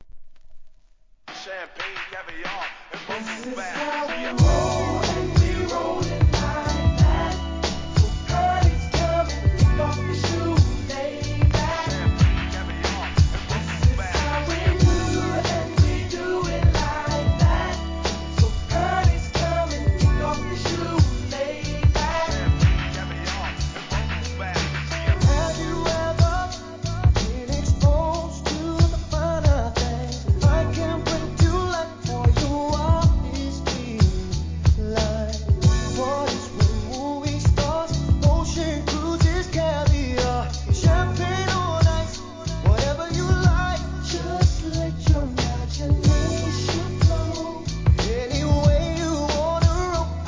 HIP HOP/R&B
ラティーノ5人組のヴォーカル・グループ!